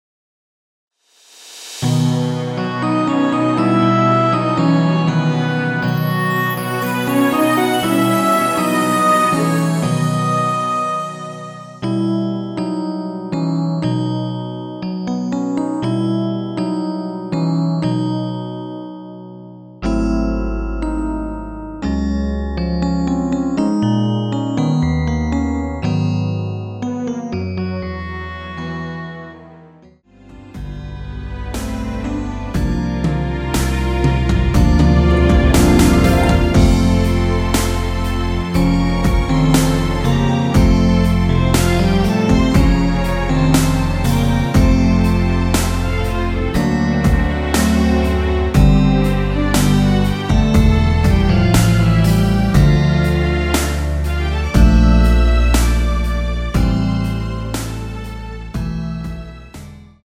앞부분30초, 뒷부분30초씩 편집해서 올려 드리고 있습니다.